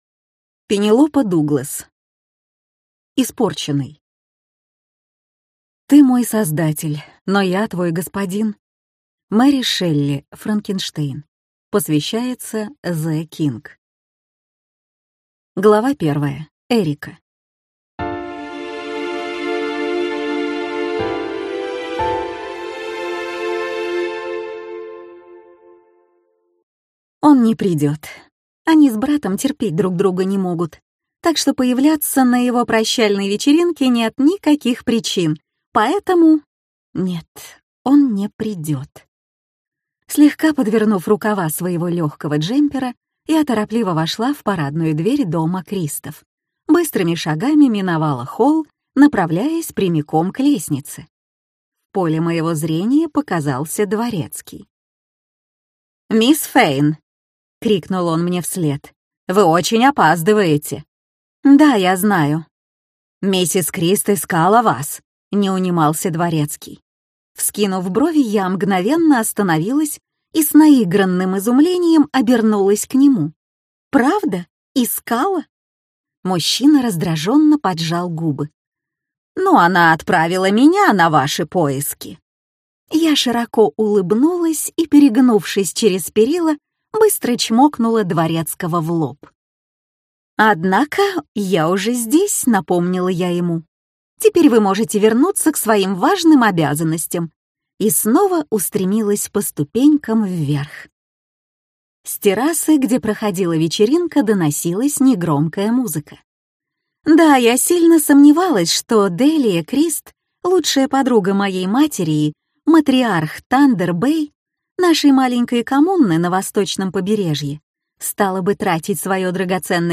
Аудиокнига Испорченный - купить, скачать и слушать онлайн | КнигоПоиск